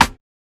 SNARE (COFFEE BEAN).wav